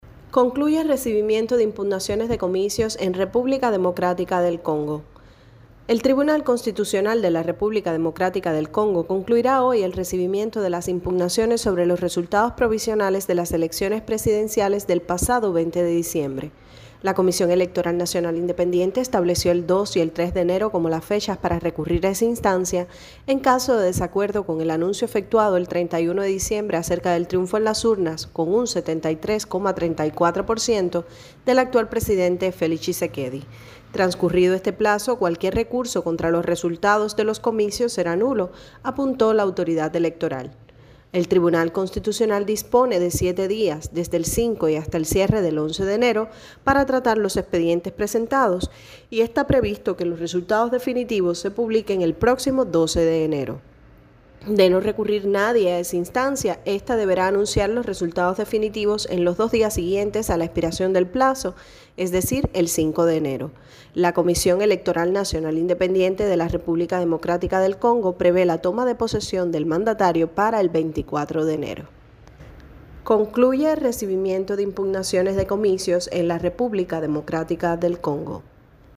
desde Kinshasa